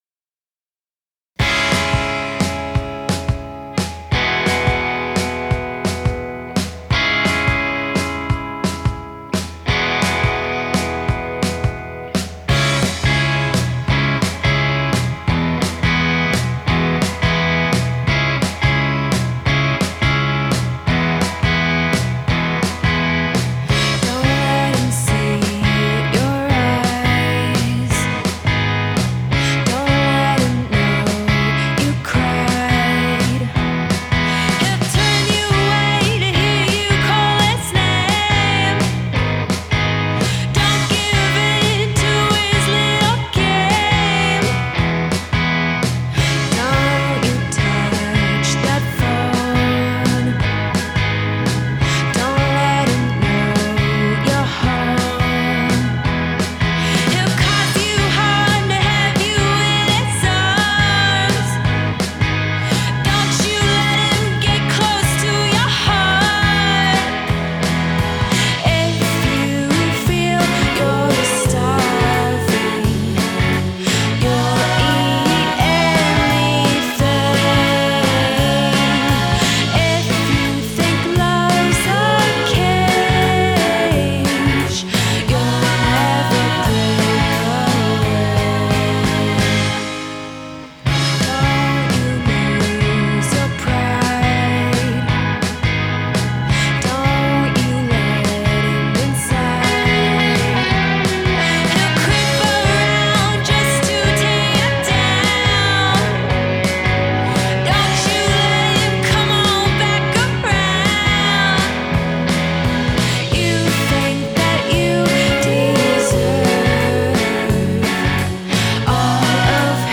Genre: indie pop, dream pop, indie rock